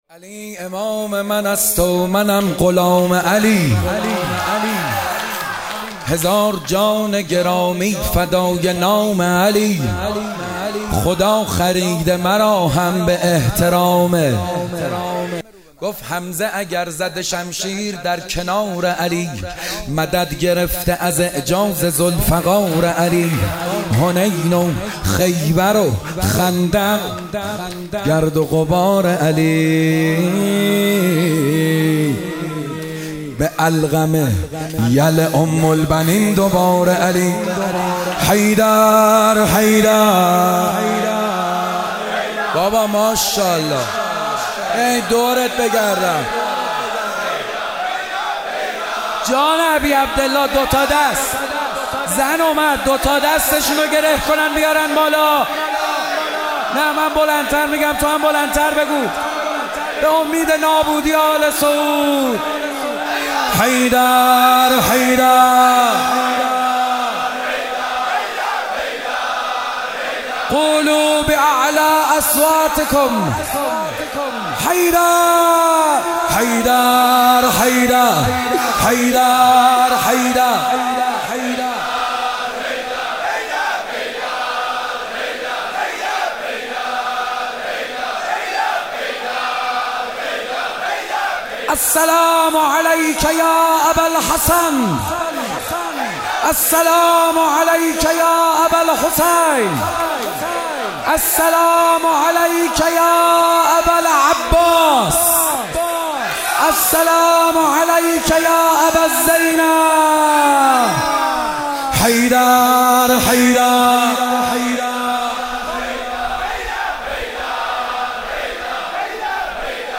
خیمه گاه - کربلایی محمدحسین حدادیان - فاطمیه 97 - روایت اول - شب دوم - مدح